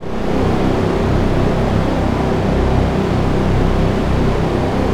AMB WASH R.wav